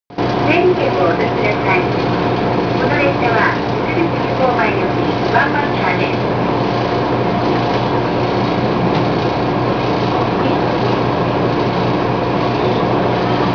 MRT300形　駅停車中の放送